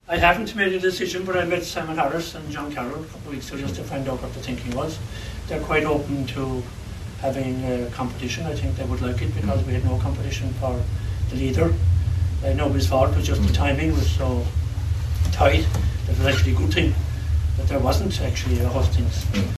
Speaking in Brussels, MEP Seán Kelly didn’t rule himself out: